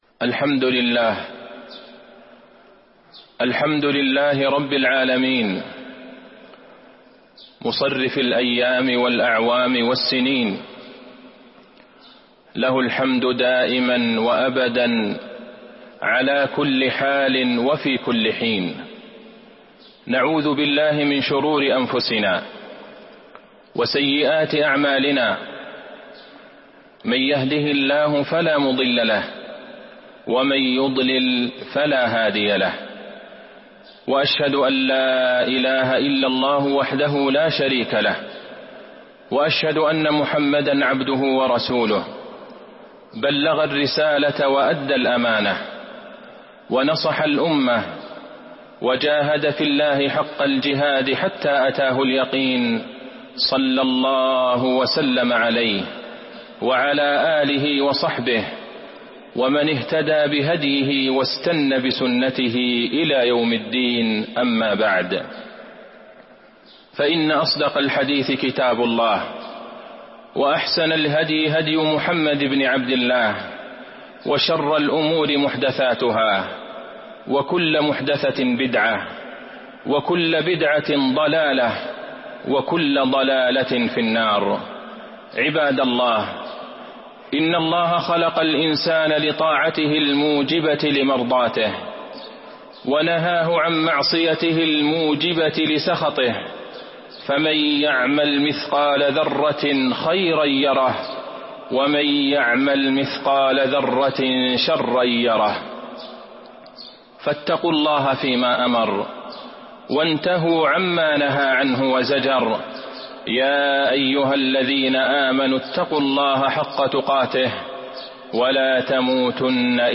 تاريخ النشر ١٠ جمادى الأولى ١٤٤٢ هـ المكان: المسجد النبوي الشيخ: فضيلة الشيخ د. عبدالله بن عبدالرحمن البعيجان فضيلة الشيخ د. عبدالله بن عبدالرحمن البعيجان الشتاء ربيع المؤمنين